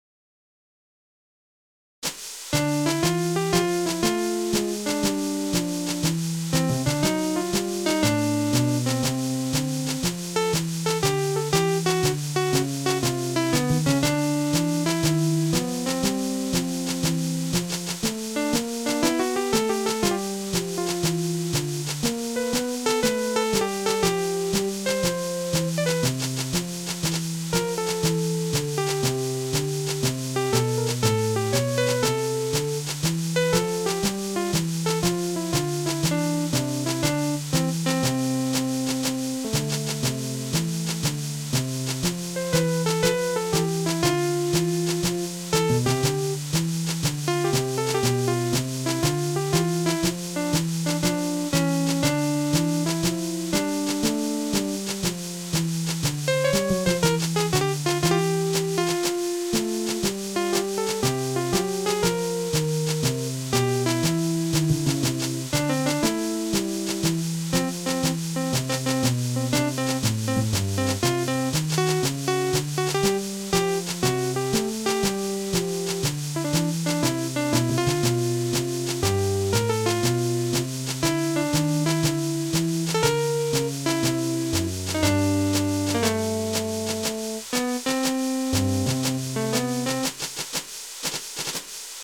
BGM
ショートジャズ